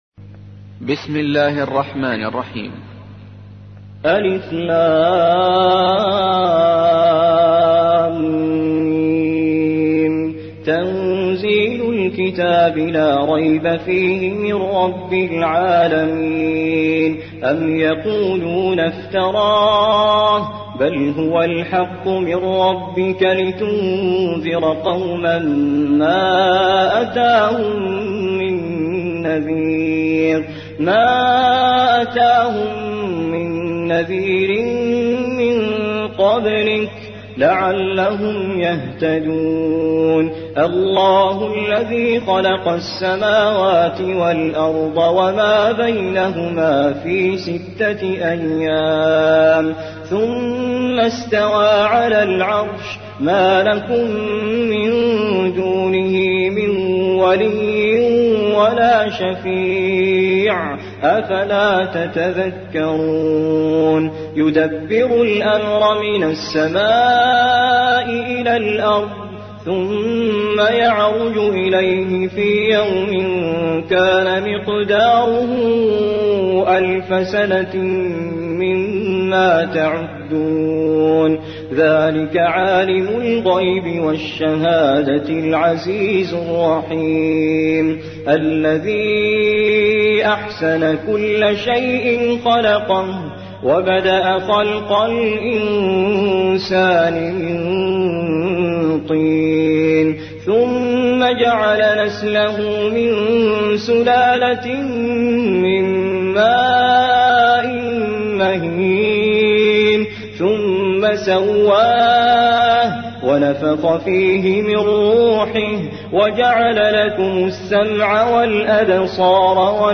تحميل : 32. سورة السجدة / القارئ توفيق الصايغ / القرآن الكريم / موقع يا حسين